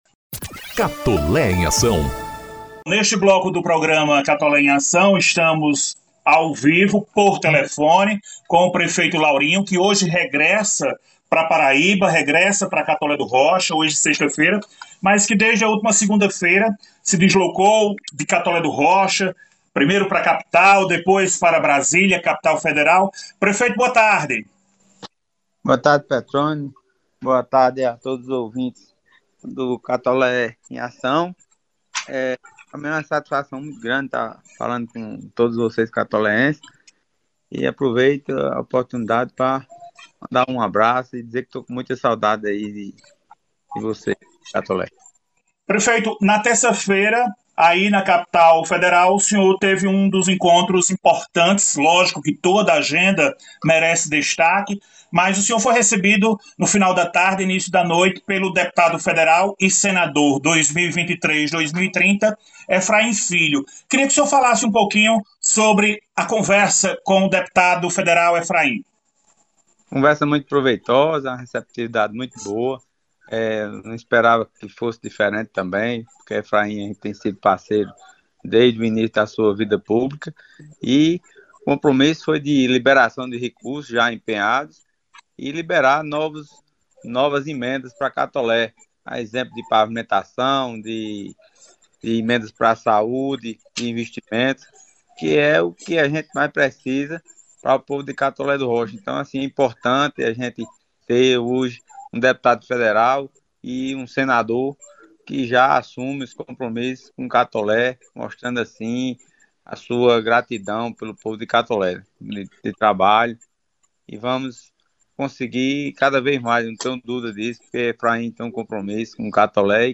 O Programa Institucional “Catolé em Ação” – edição n° 68 – entrevistou o prefeito Laurinho Maia, direto de Brasília (DF).